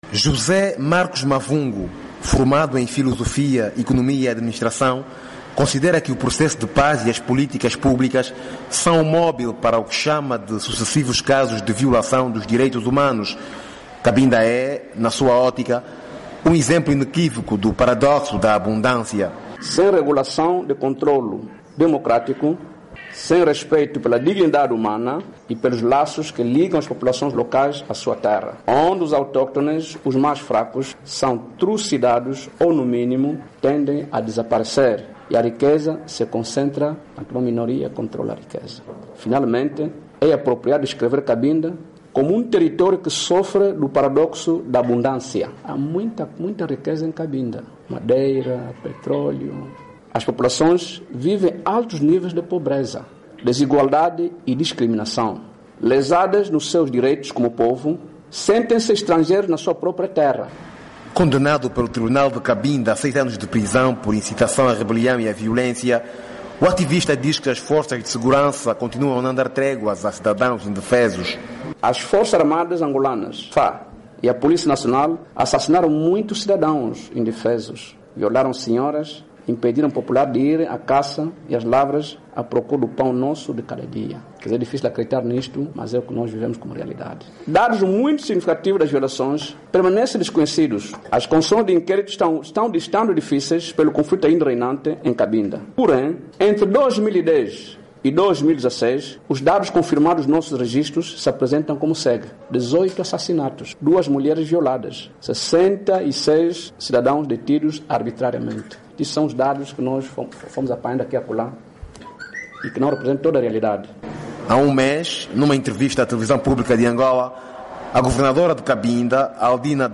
Activista de Cabinda fez estas declarações nas Quintas de Debate, na OMUNGA, em Benguela.